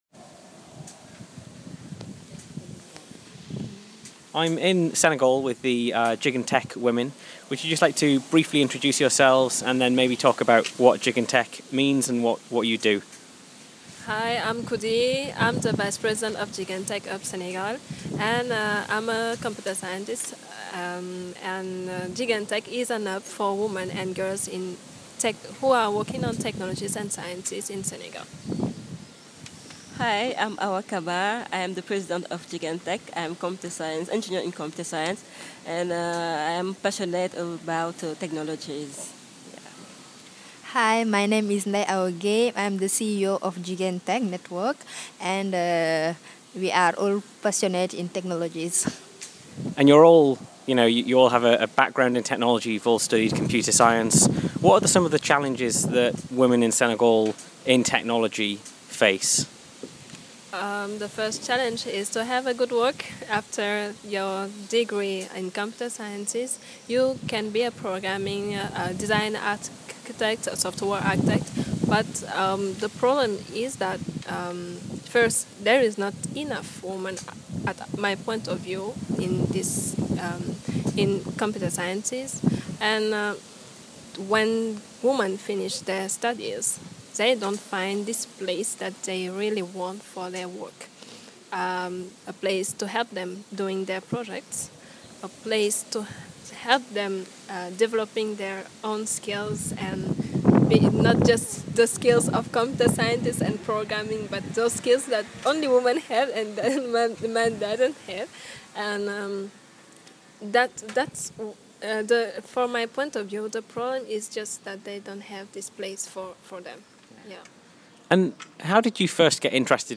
Chatting with the Jjiguene Tech women in Dakar, Senegal